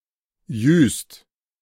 Juist (German pronunciation: [ˈjyːst]